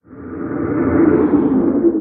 Minecraft Version Minecraft Version latest Latest Release | Latest Snapshot latest / assets / minecraft / sounds / entity / guardian / ambient3.ogg Compare With Compare With Latest Release | Latest Snapshot